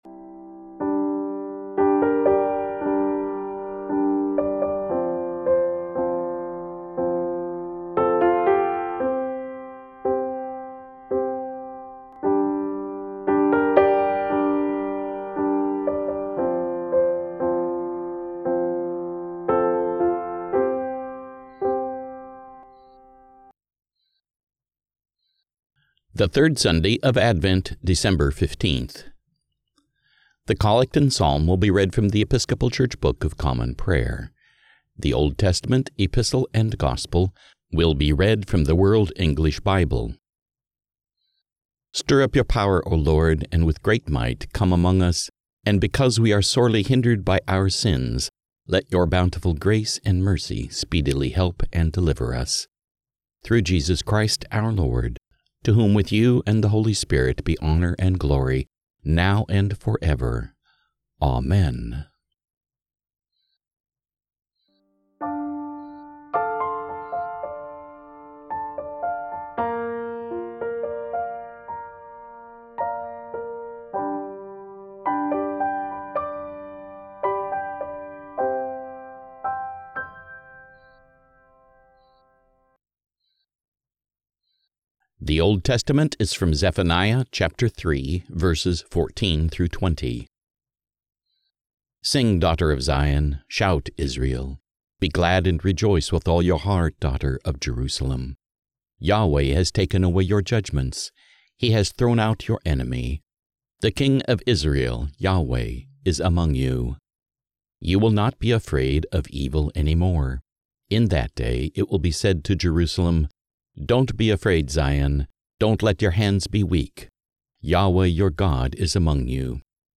The Collect and Psalm will be read from The Episcopal Church Book of Common Prayer
The Old Testament, Epistle and Gospel will be read from the World English Bible